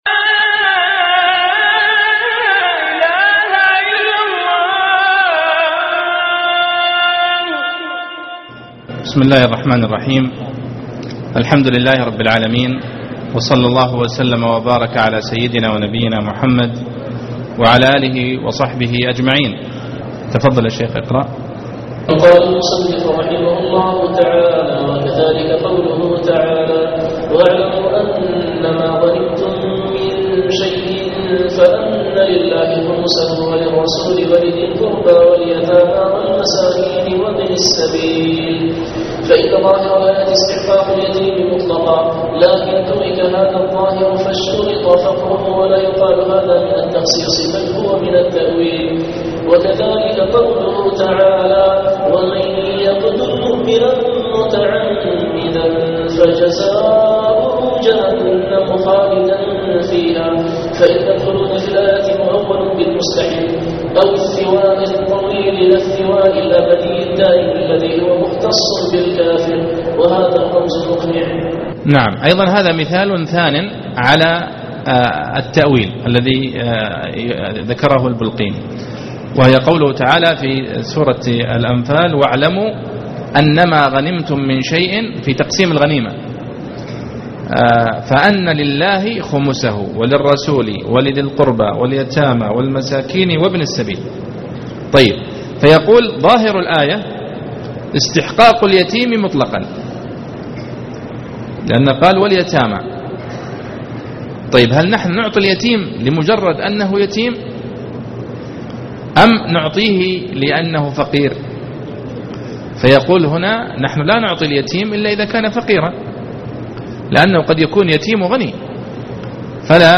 أنواع علوم القرآن-المفهوم( 19/6/2014)دروس من الحرم